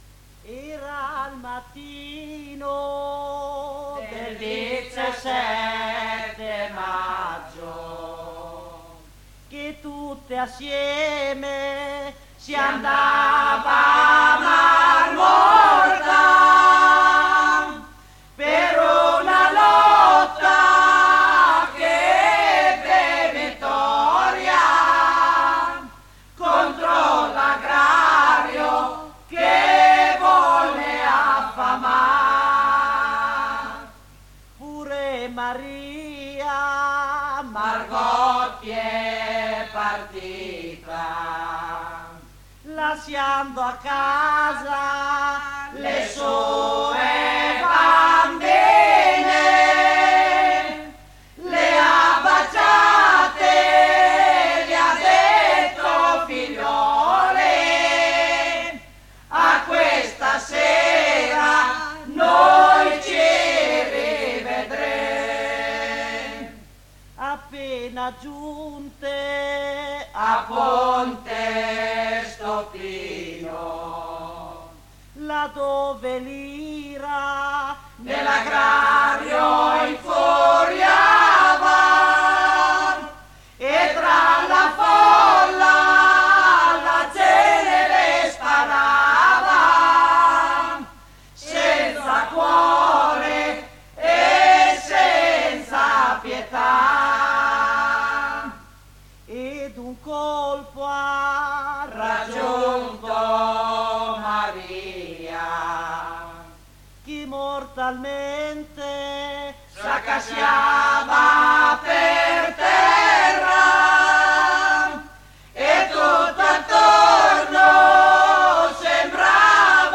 Registrazioni dal vivo e in studio, 1982 circa